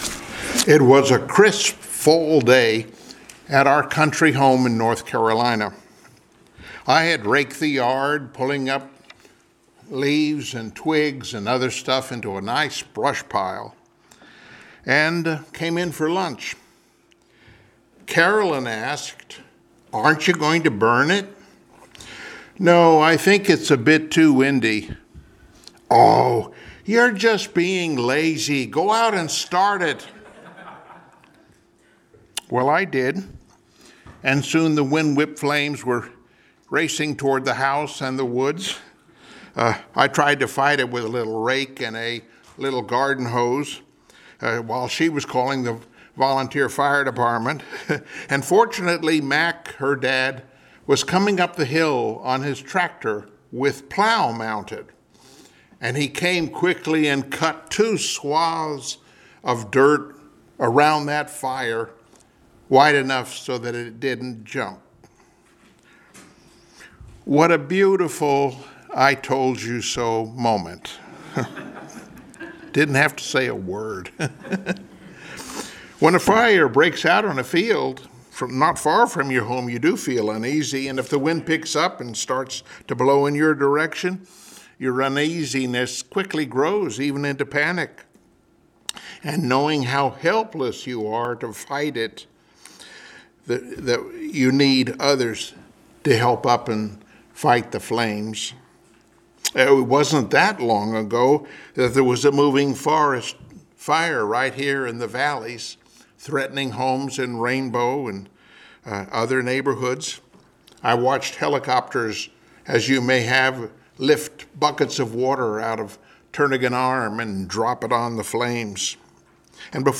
Passage: Psalm 11 Service Type: Sunday Morning Worship Topics